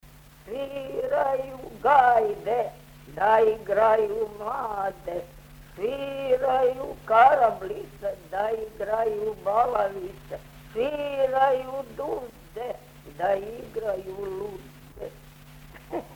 Тема: Припеви, бећарци, кратке, потркушице, шалајке, шаљиве, набрајалице, песме из механе, песме за плес
Место: Сечуј
Напомена: Кратки гајдашки припеви, у речитативном маниру који се изводе уз игру, изведени су практично само са метро-ритмичком, а не и мелодијском компонентом. Изразито дубока интонација и необична импостација за женски глас могу бити последица њеног напора да у ситуацији снимања постигне што ауторитативнији звук сопственог гласа.